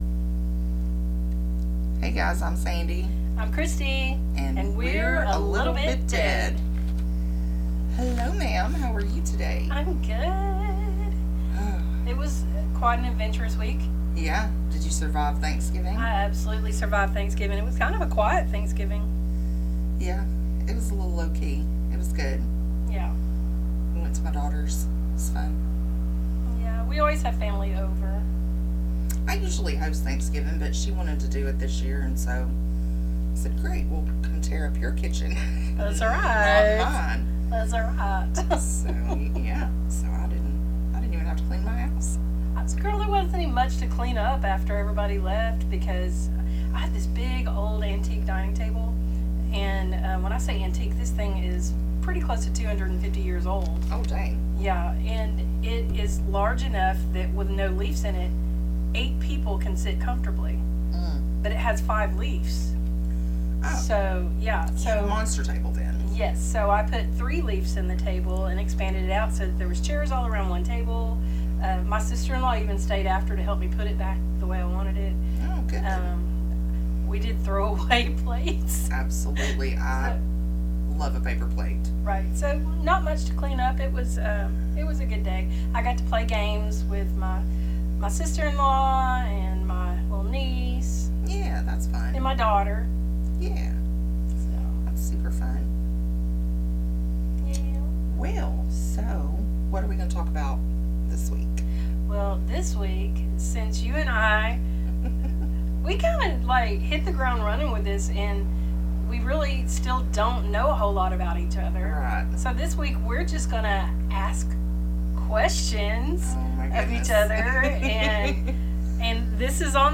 We did not release the edited version due to said technical difficulties so please excuse the coughing!